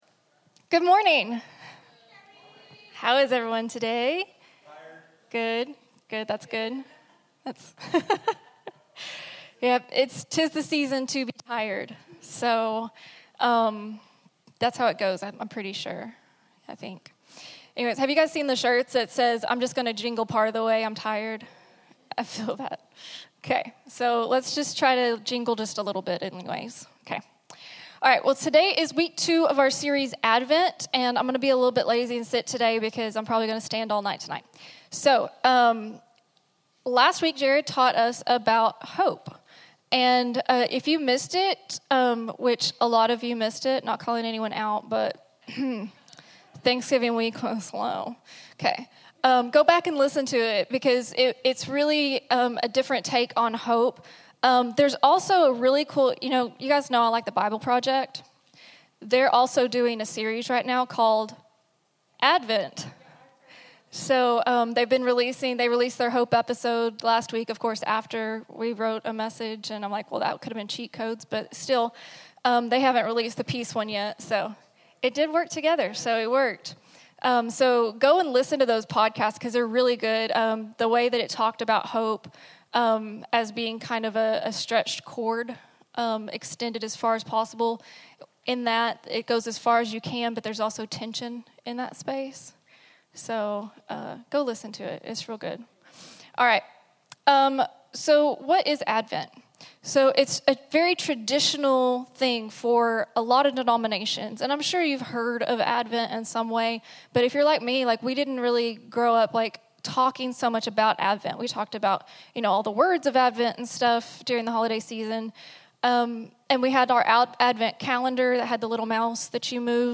Download Download Sermon Notes Message Notes.pdf Kid Lesson Notes.pdf Peace isn’t the removal of chaos; it’s the presence of Christ within it.